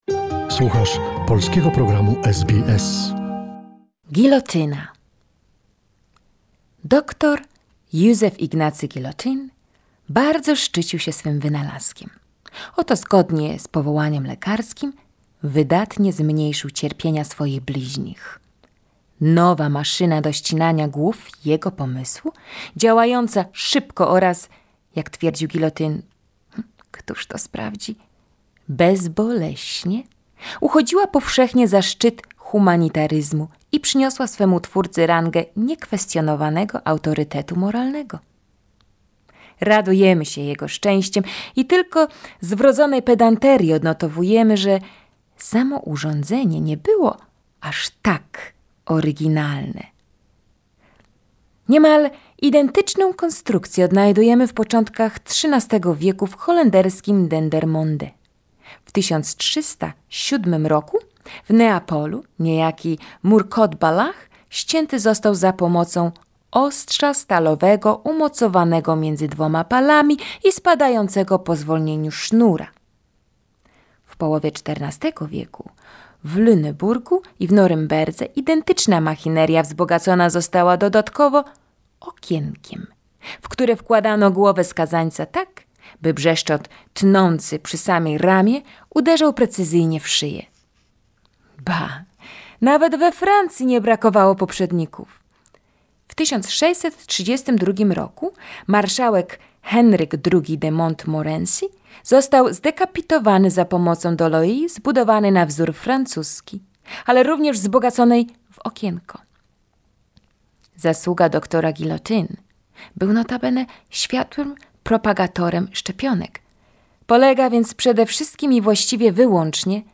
The story of 'Guillotine' is from the book 'Lives of playful sentences' by anthropologist Prof. Ludwik Stomma. We present archival recordings of fragments of the book